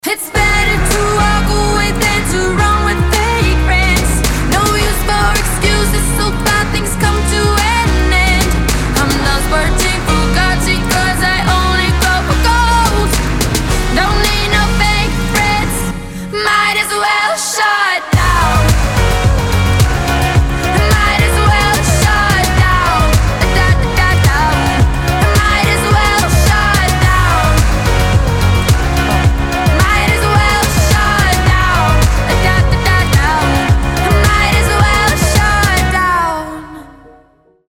поп
громкие
женский вокал
dance
indie pop